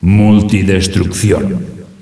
multikill.ogg